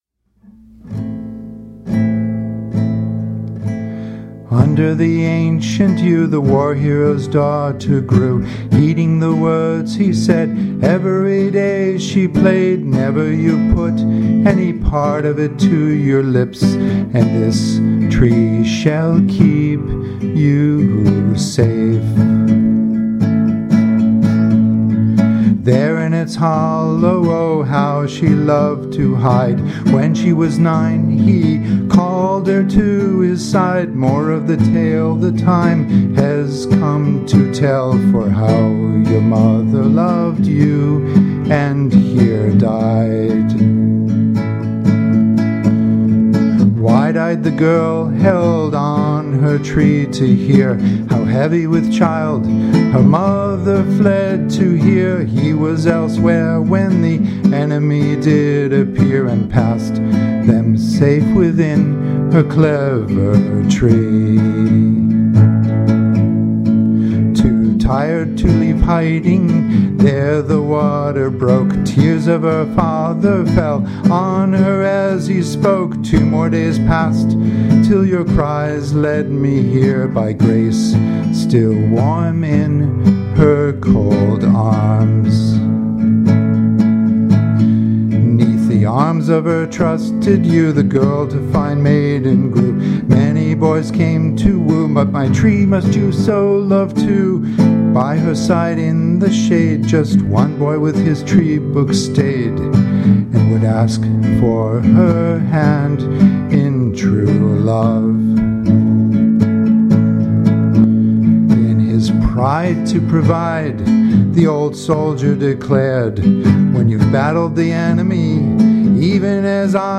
Guitar chords pretty simple, Am - G, Am - G - D, Am - G, Am - G - A